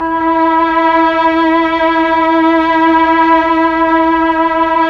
Index of /90_sSampleCDs/Keyboards of The 60's and 70's - CD1/STR_Melo.Violins/STR_Tron Violins
STR_TrnVlnE_4.wav